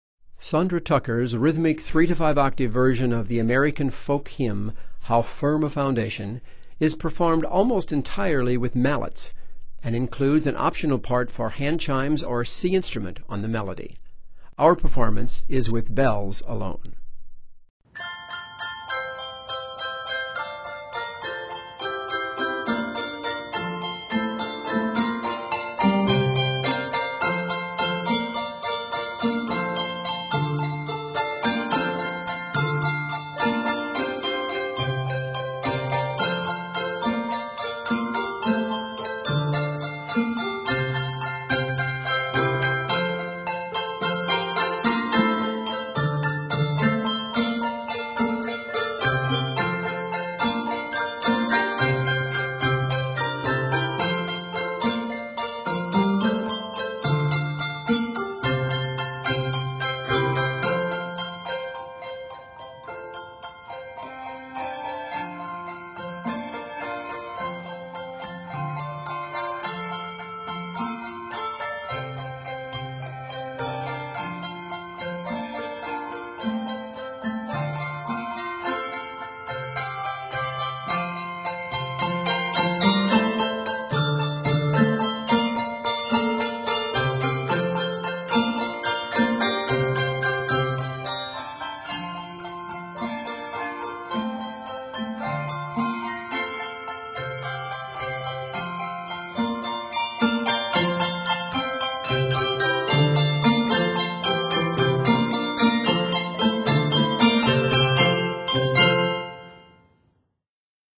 Arranged in F Major, measures total 41.